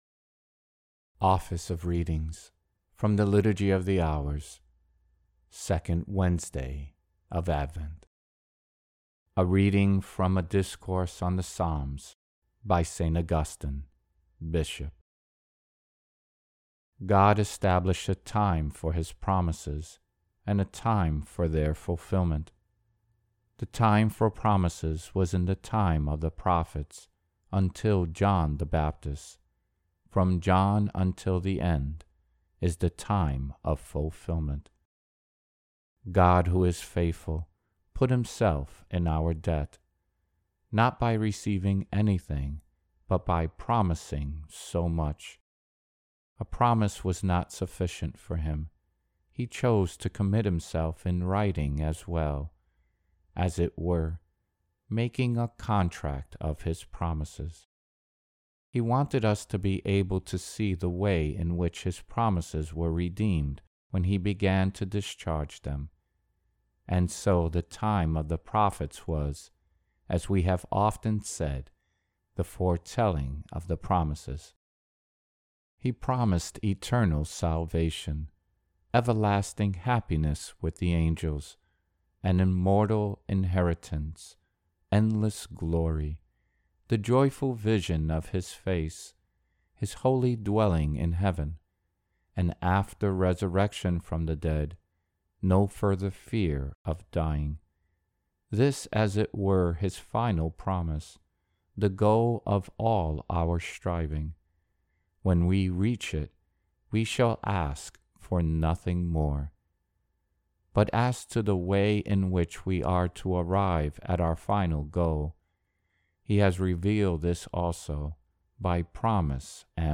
Office-of-Readings-2nd-Wednesday-of-Advent.mp3